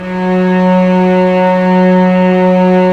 Index of /90_sSampleCDs/Roland LCDP13 String Sections/STR_Vcs II/STR_Vcs6 p Amb